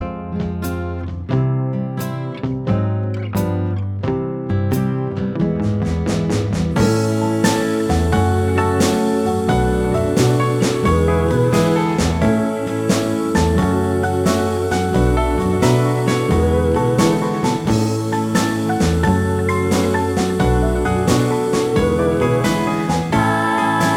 Minus Bass Guitar Pop